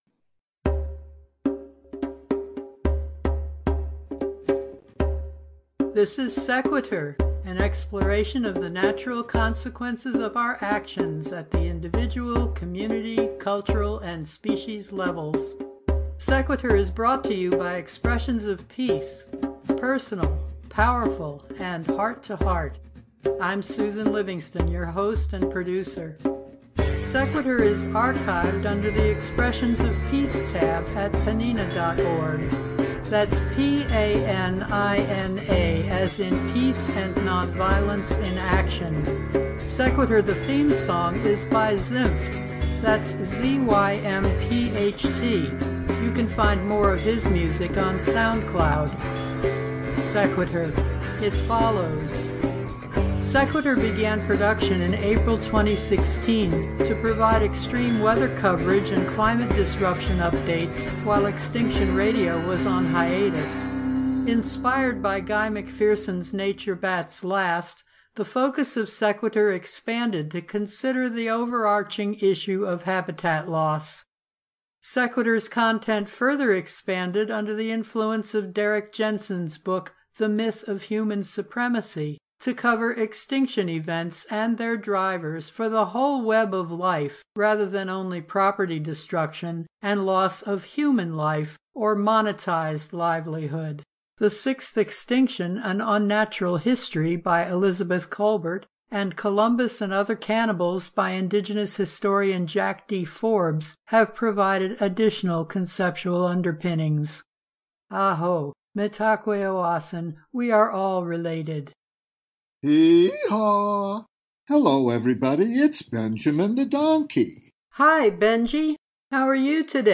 Musical guest
A Capella Science parody